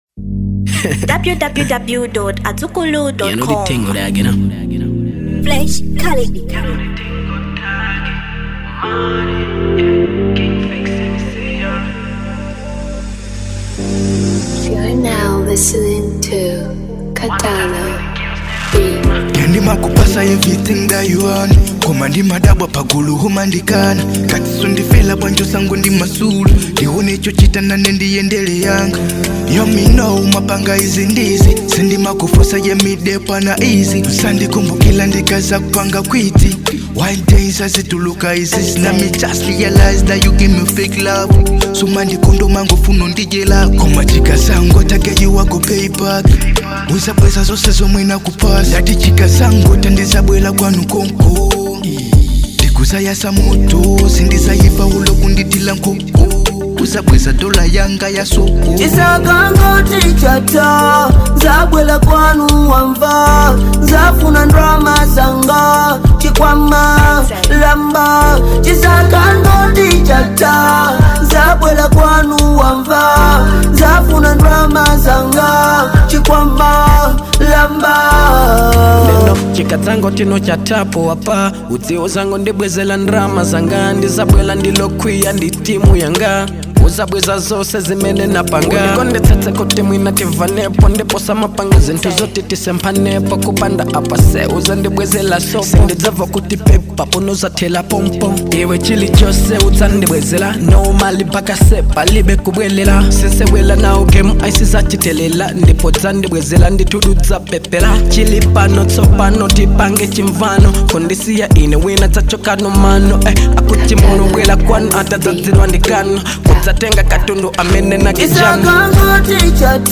Genre Reggae & Dancehall